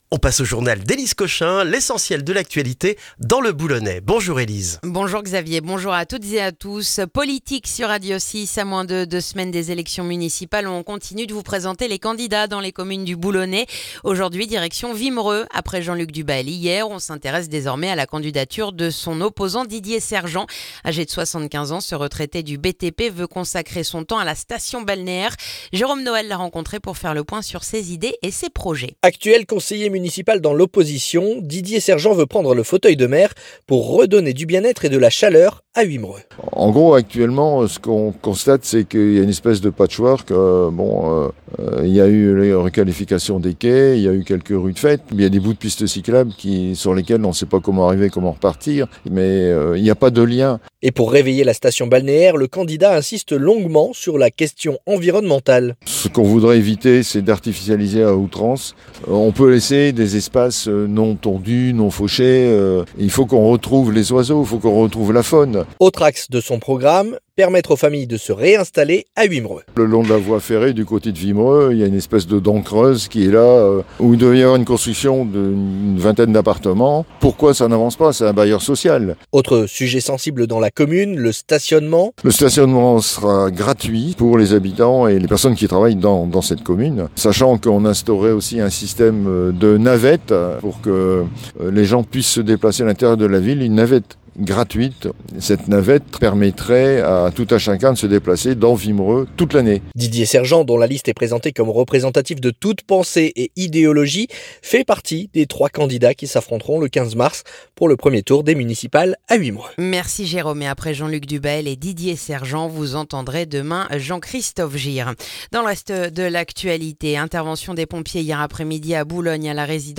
Le journal du jeudi 5 mars dans le boulonnais